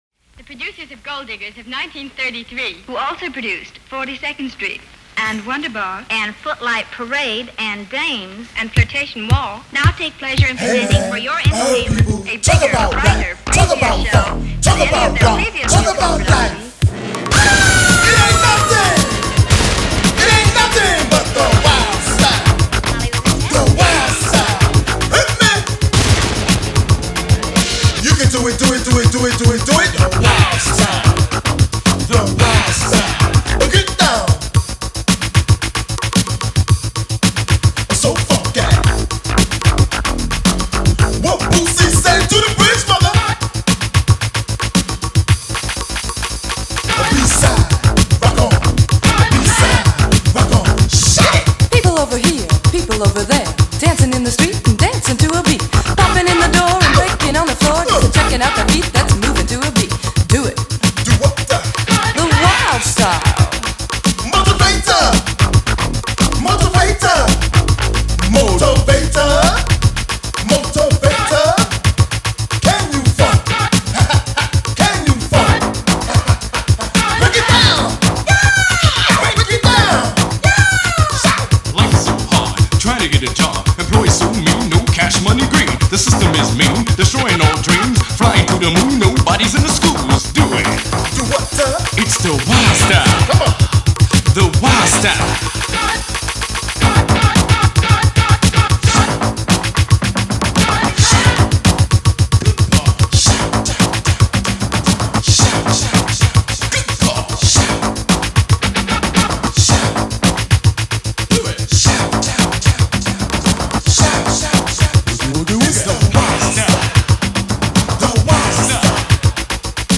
Old-school bis, tu danses le funk